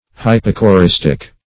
Search Result for " hypocoristic" : The Collaborative International Dictionary of English v.0.48: Hypocoristic \Hyp`o*co*ris"tic\, a. [Gr.